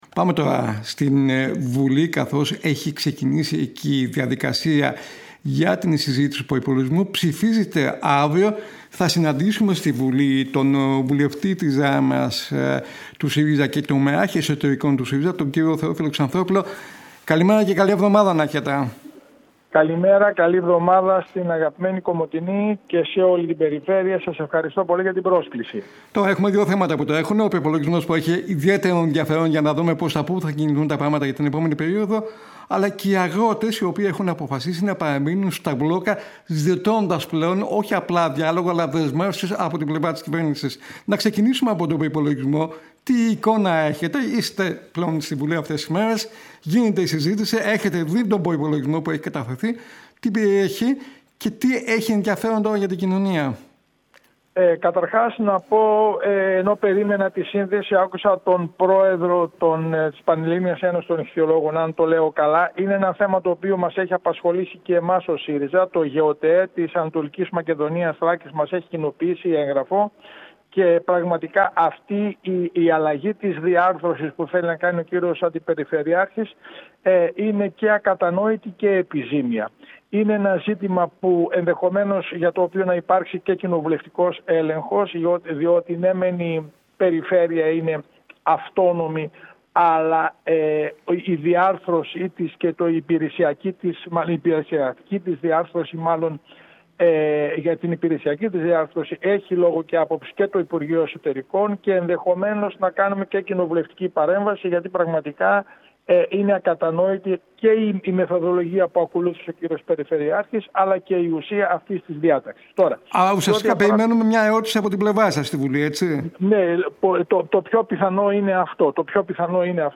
Θ. Ξανθόπουλος στην ΕΡΑ Κομοτηνής: Ο προϋπολογισμός της κυβέρνησης μεγεθύνει τις ανισότητες, δεν απαντά στα φλέγοντα προβλήματα της κοινωνίας-Ο αγώνας των αγροτών αφορά όλη τη κοινωνία-Πρωτεύουσα των Χριστουγέννων στη περιοχή μας η Δράμα με την Ονειρούπολη